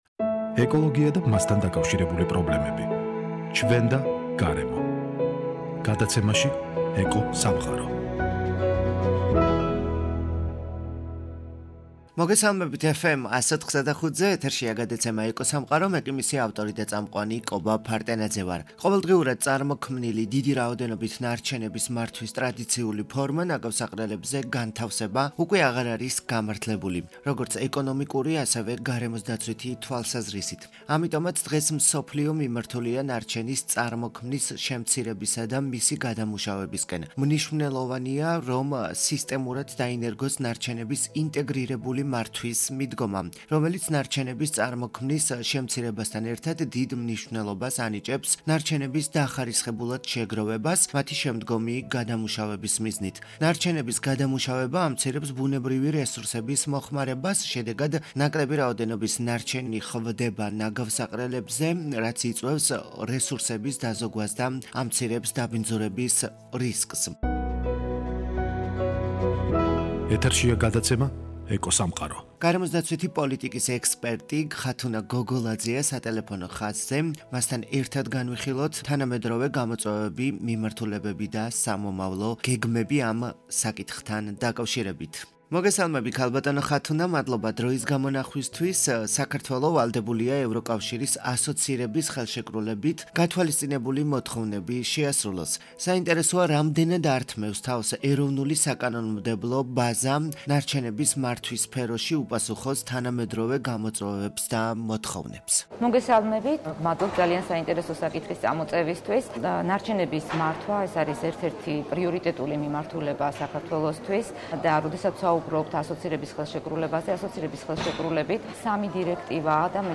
გარემოსდაცვითი პოლიტიკის ექსპერტი